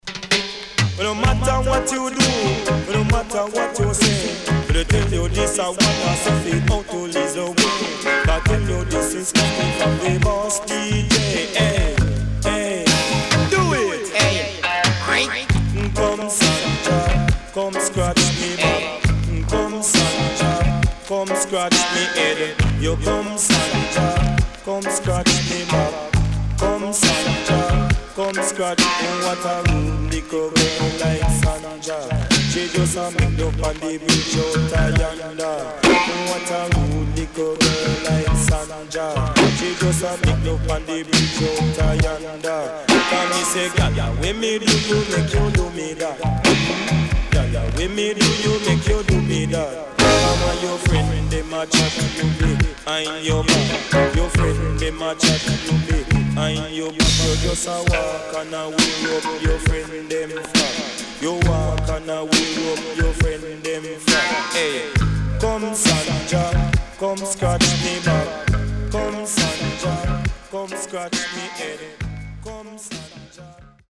HOME > Back Order [DANCEHALL DISCO45]  >  EARLY 80’s
Nice Deejay on One Drop
SIDE A:序盤少しノイズ入りますが落ち着きます。